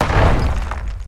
bomb2.ogg